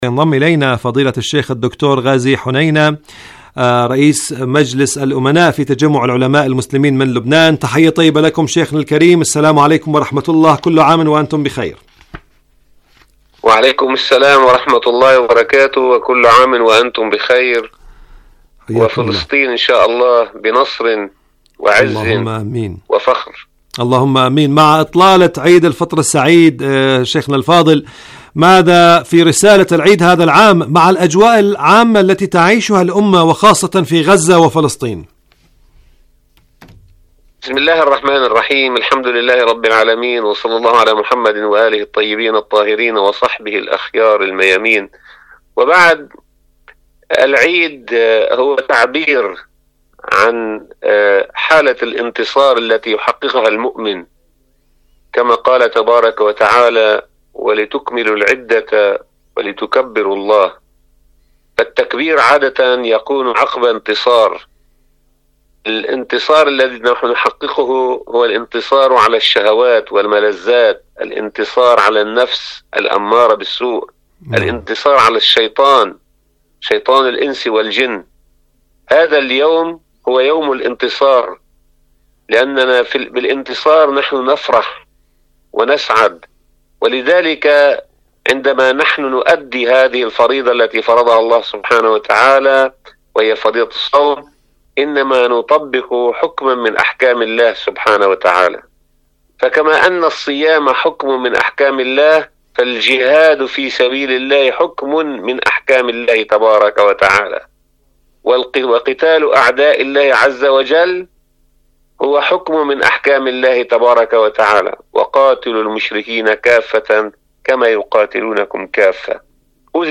مقابلات برامج إذاعة طهران العربية برنامج فلسطين اليوم مقابلات إذاعية القدس الشريف الكيان الاحتلال المسجد الأقصي ماذا في عيدي فلسطين؟